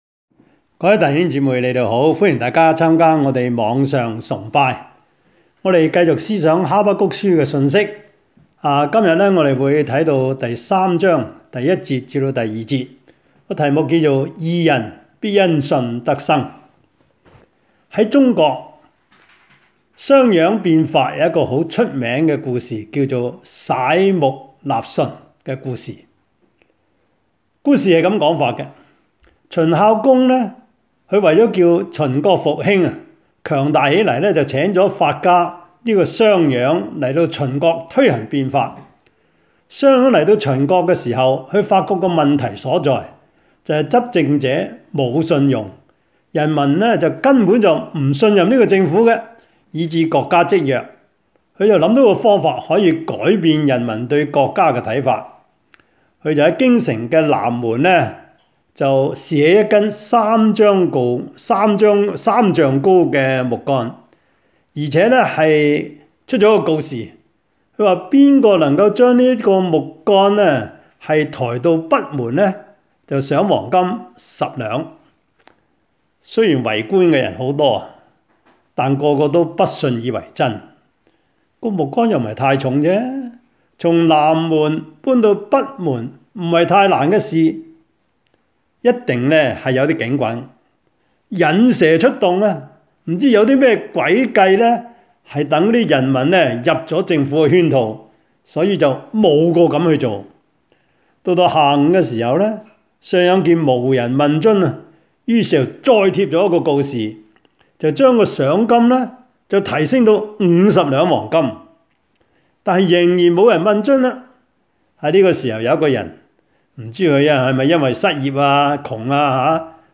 疫症中之哈巴谷書講道系列
Habakkuk-Sermon-6.mp3